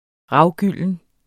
Udtale [ ˈʁɑwˌgylˀən ]